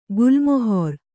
♪ gulmohor